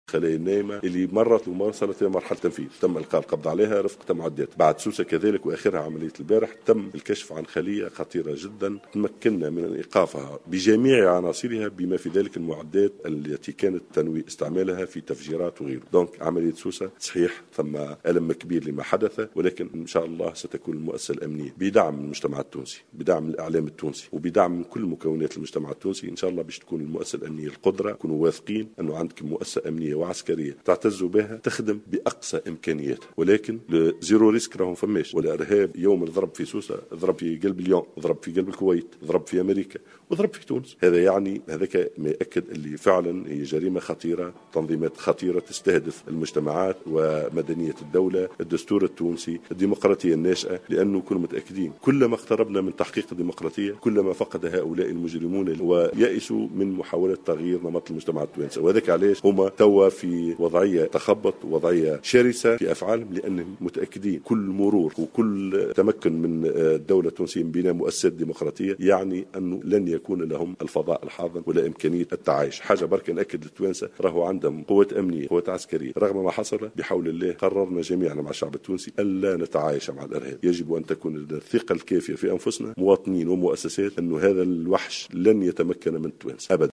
أكد وزير الداخلية محمد ناجم الغرسلي مساء أمس في تصريح لمراسل جوهرة" اف ام" اثر جلسة استماع له في مجلس نواب الشعب الكشف عن عدد من الخلايا النائمة التي كانت تنوي المرور إلى مرحلة تنفيذ هجمات ارهابية بعد عملية سوسة على حد قوله.